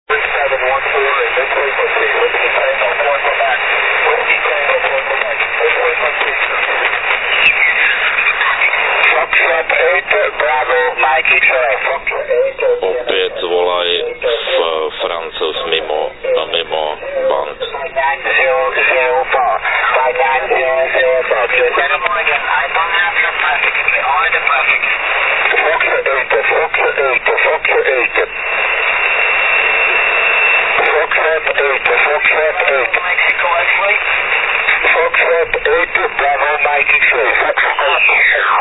Všechny nahrávky pocházejí z FT817.